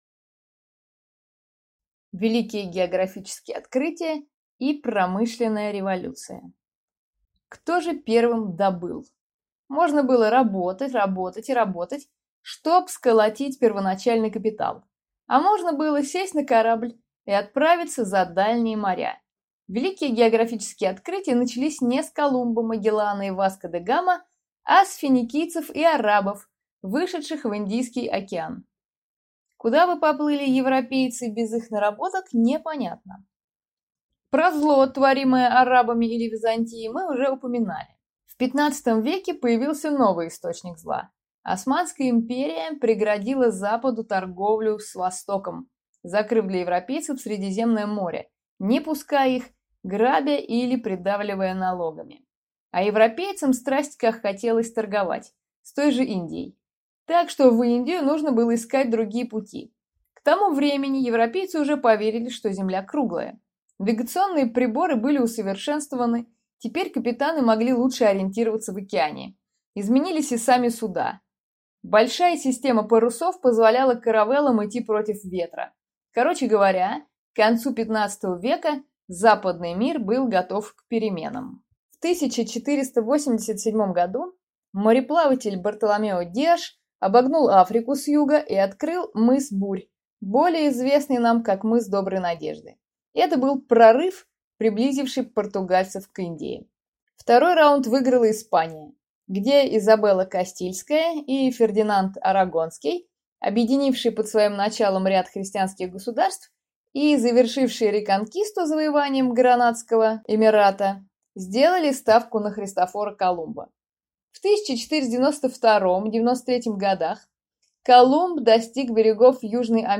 Аудиокнига Великие географические открытия и Промышленная революция | Библиотека аудиокниг